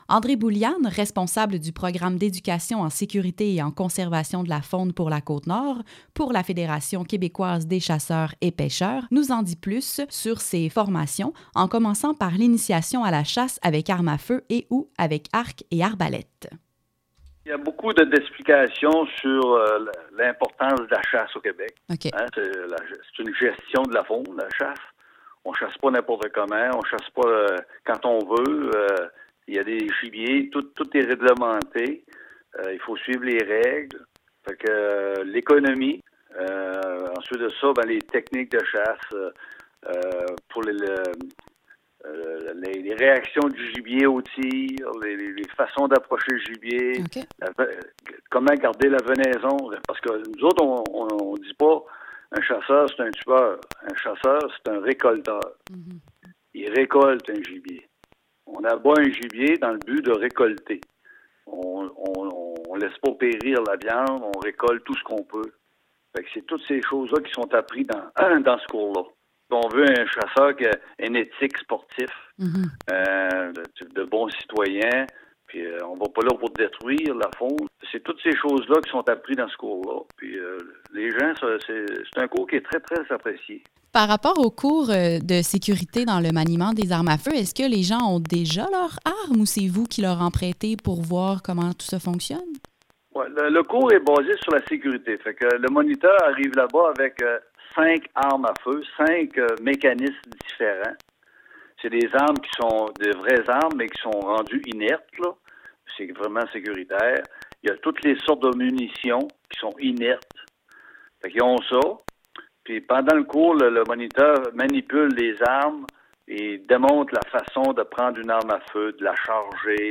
Segment-radio-formation-chasse.mp3